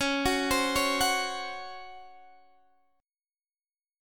C#M7sus4 chord